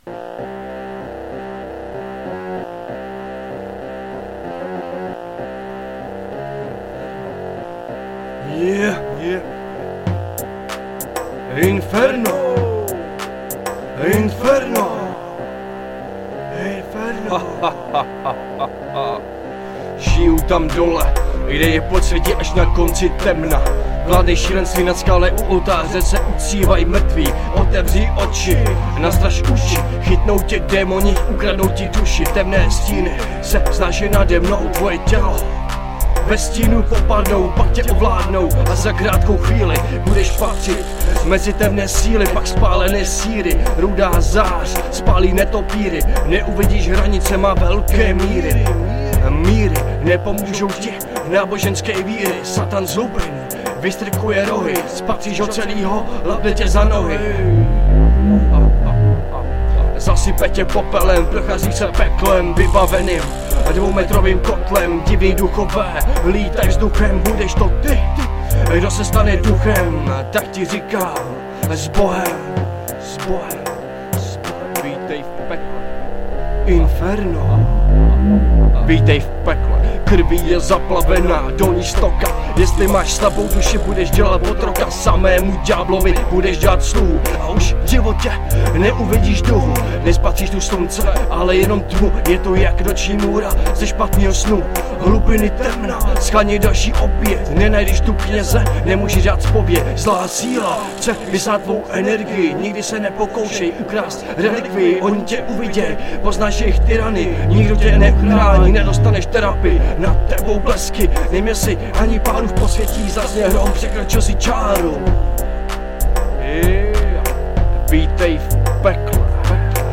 Žánr: Hip Hop/R&B